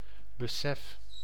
Ääntäminen
US : IPA : [ə.ˈwɛə(r).nəs]